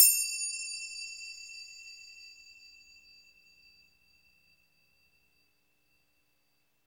CYM FNGR.C05.wav